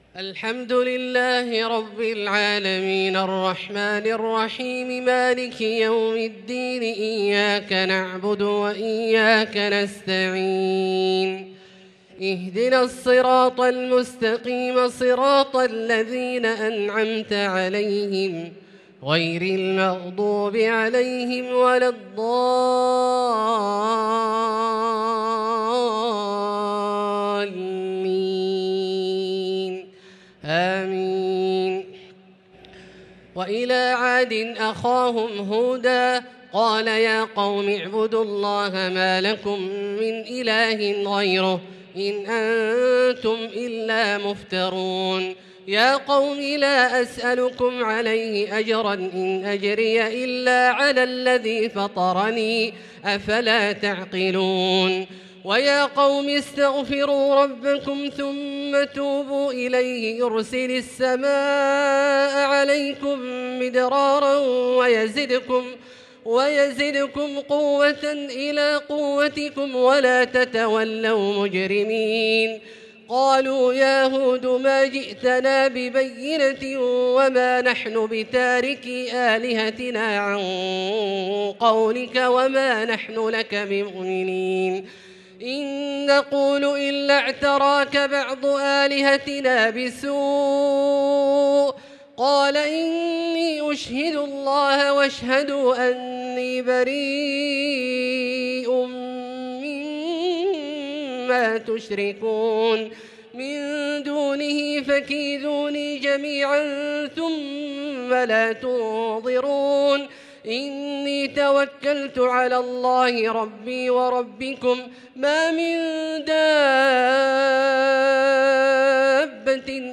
صلاة التراويح ليلة 16 رمضان 1443 للقارئ عبدالله الجهني - الأربع التسليمات الأولى صلاة التراويح